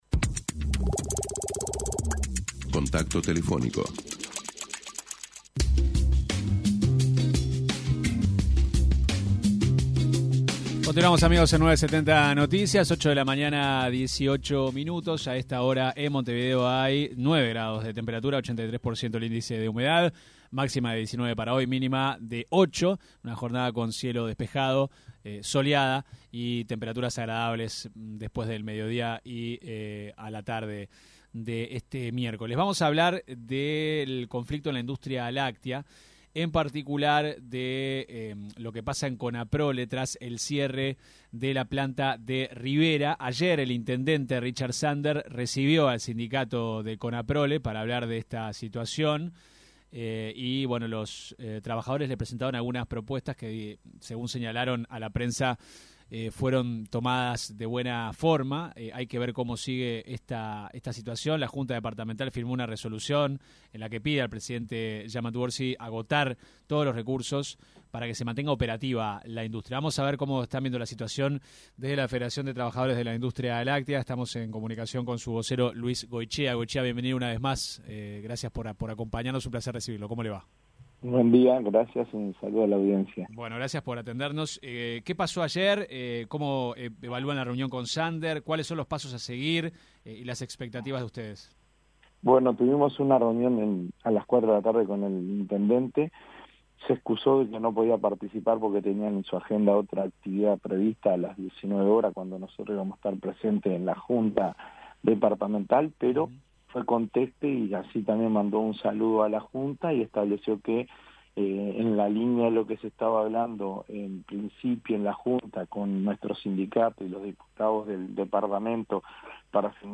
en entrevista con 970 Noticias